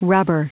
Prononciation du mot rubber en anglais (fichier audio)
Prononciation du mot : rubber